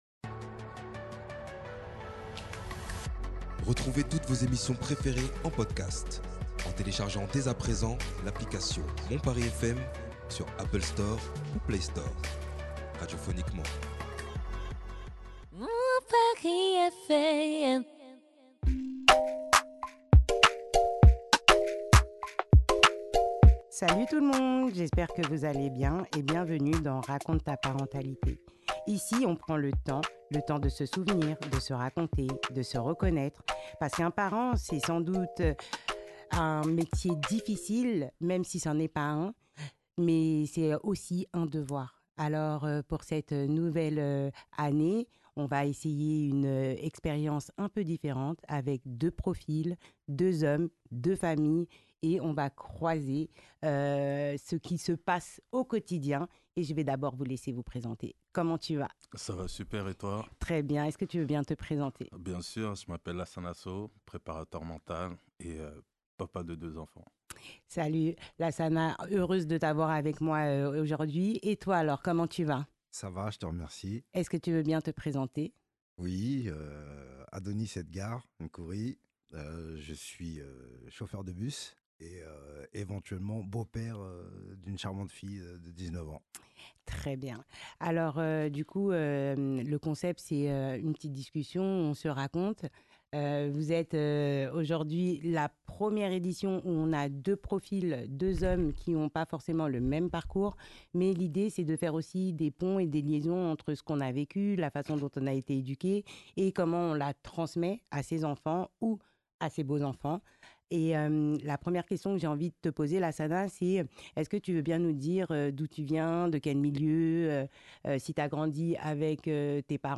À travers leurs récits, ils nous ouvrent les portes de leur enfance, de leur éducation, et nous livrent la manière dont ces expériences ont façonné les hommes et les pères qu’ils sont devenus aujourd’hui. Alors, prenez une bonne inspiration, servez-vous une tasse de thé, installez-vous confortablement… et laissez-vous porter par cette conversation sincère, profonde et inspirante.